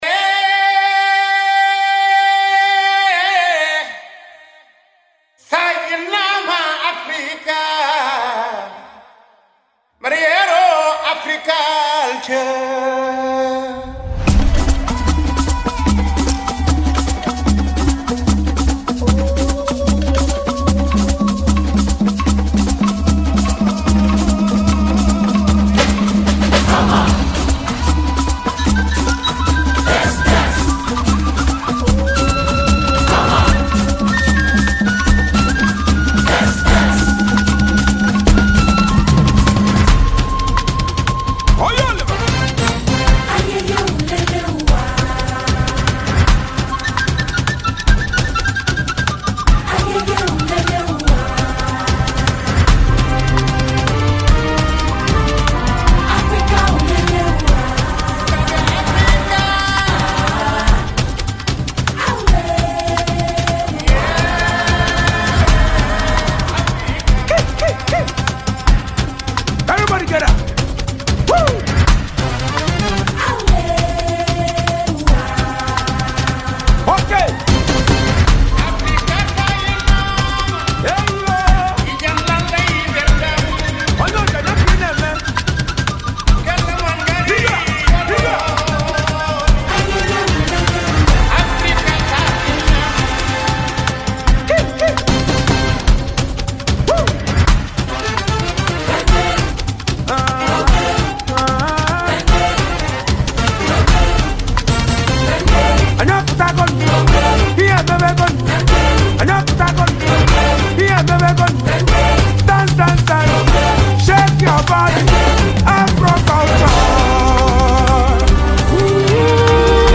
guitarist
With its lush melodies and captivating beats
combines powerful verses with irresistible melodies